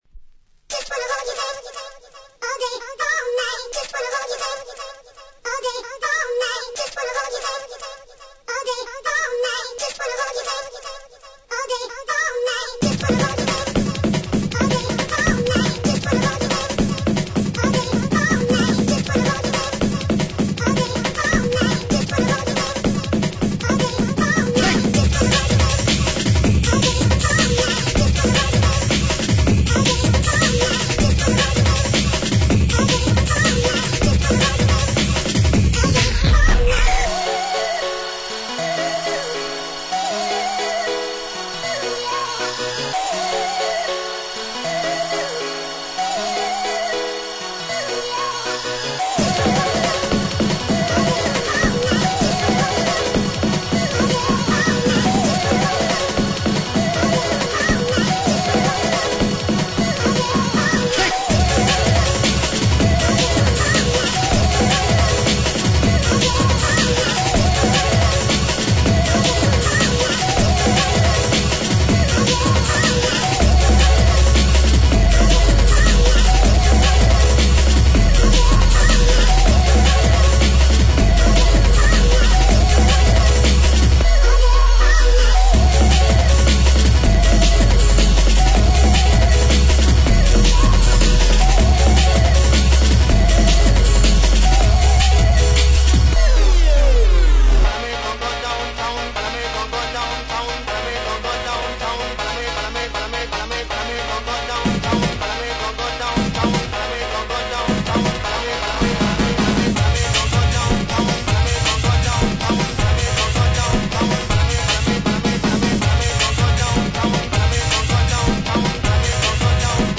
Old-Skool
Breakbeat, Hardcore